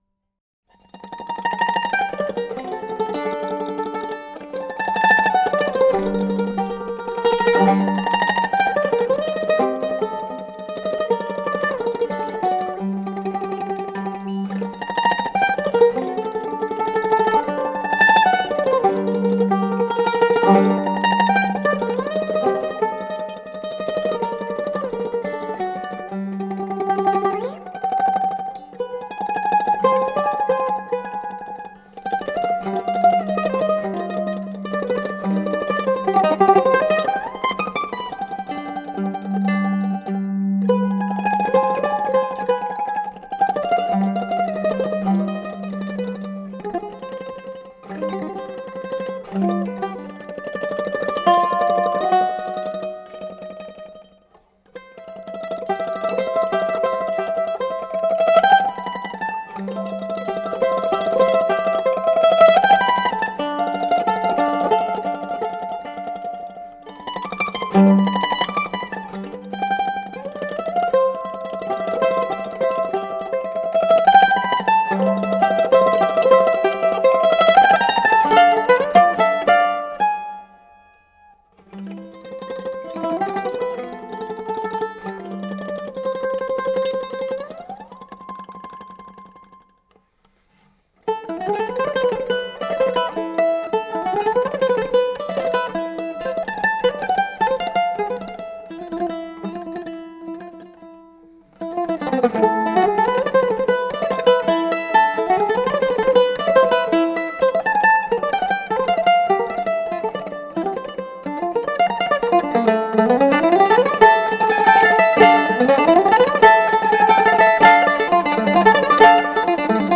solo mandolin